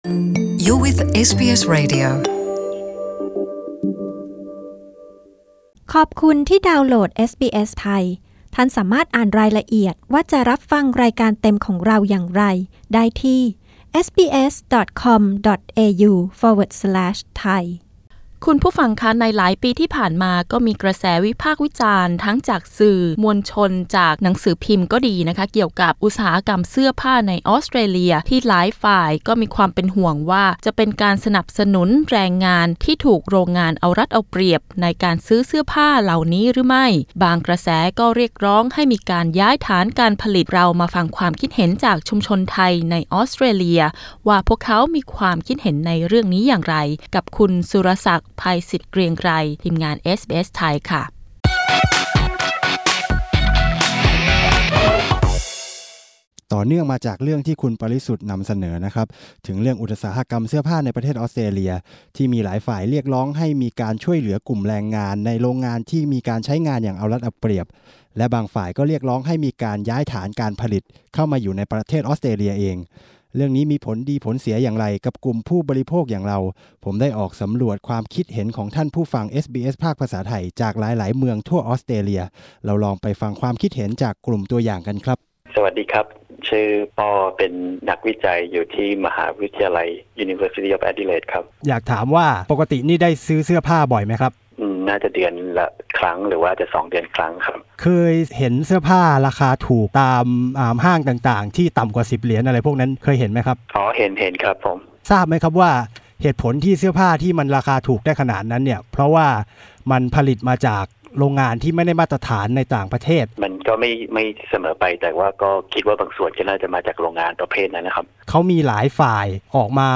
กดปุ่ม 🔊 ด้านบนเพื่อฟังสารคดีเรื่องนี้